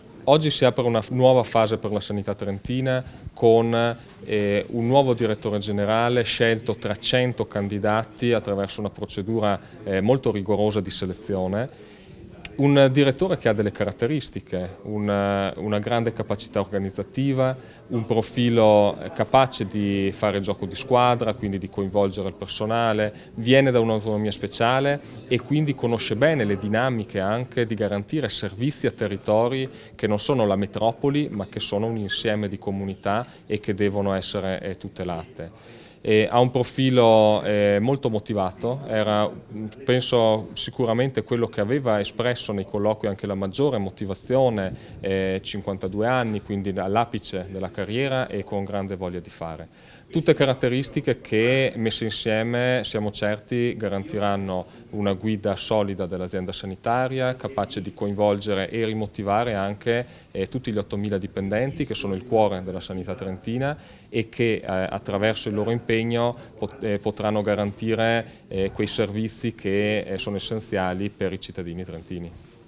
intervista_ass.Zeni.mp3